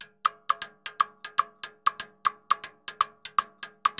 Timbaleta_Salsa 120_3.wav